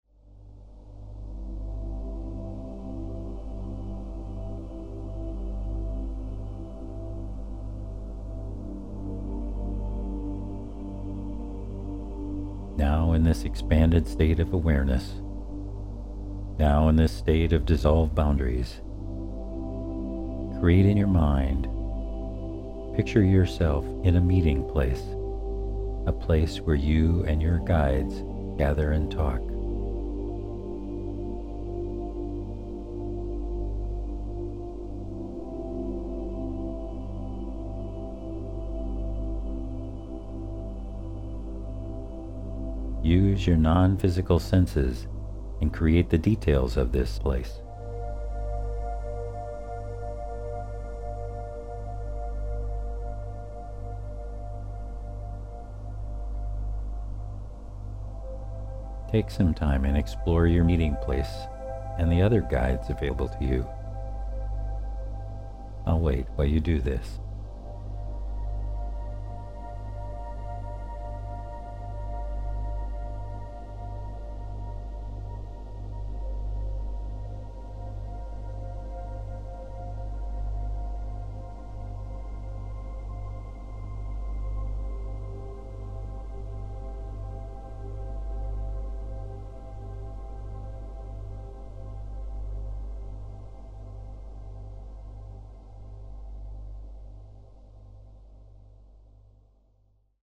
První cvičení je celé vedené a ukáže vám, jak připravit své tělo, mysl a tělesnou energii na cestu vedoucí k setkání a konverzaci s vašimi průvodci.